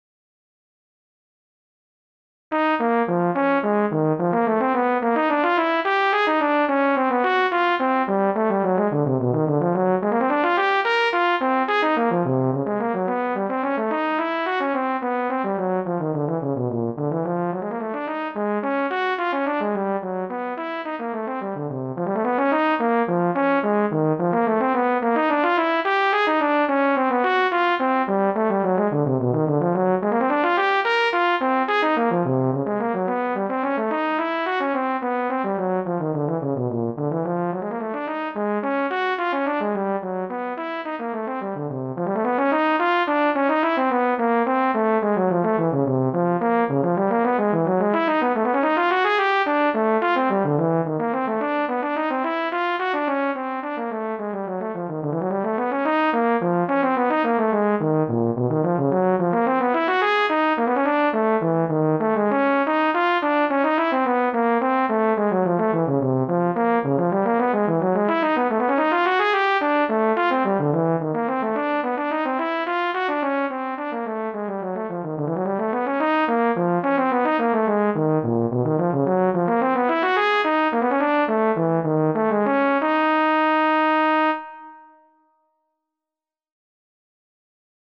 (Gigue)
2 – Pour flûte de Pan, ou autre instrument mélodique.